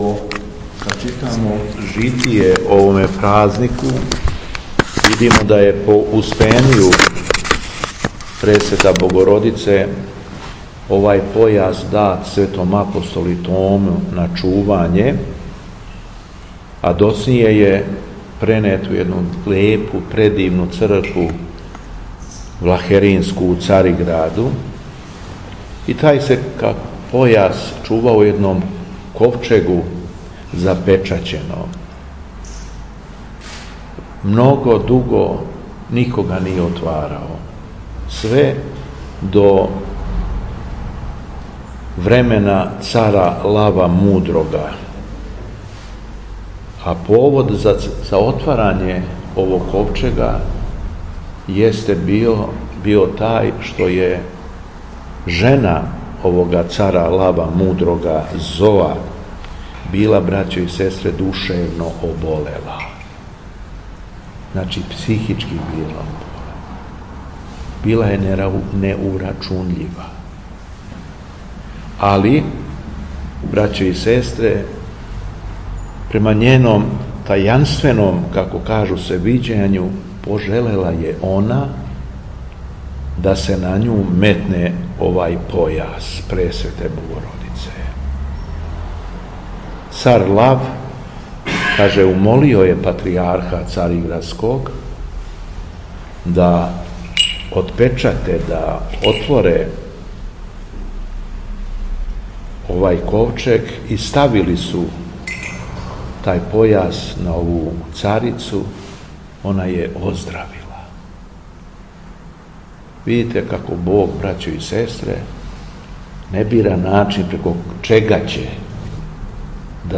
У уторак, 13. септембра 2022.год., када се наша Црква молитвено сећа плагања часног Појаса Пресвете Богородице, Његово Преосвештенство Епископ шумадијски г. Јован служио је Свету Архијерејску Литургију у храму Светог великомученика Димитрија у крагујевачком насељу Сушица уз саслужење братства овога...
Беседа Његовог Преосвештенства Епископа шумадијског г. Јована
По прочитаном Јеванђељу од Луке, Преосвећени Владика Јован се обратио верном народу: